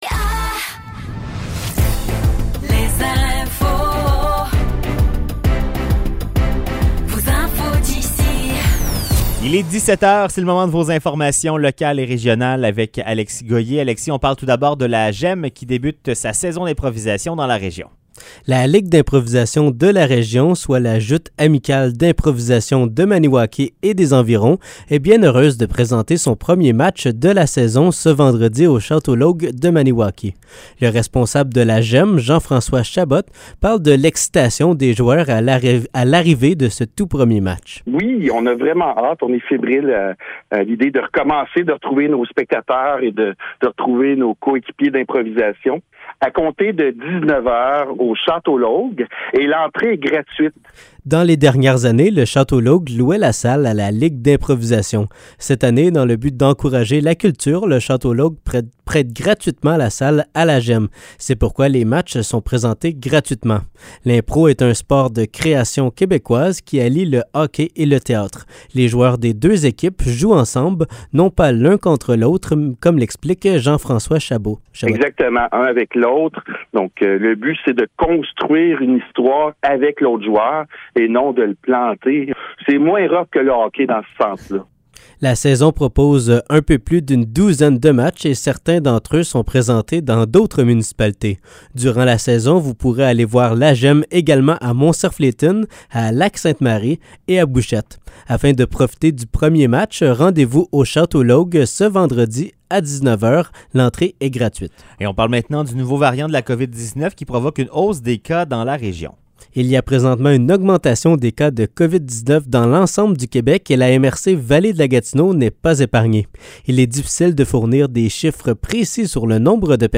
Nouvelles locales - 13 septembre 2023 - 17 h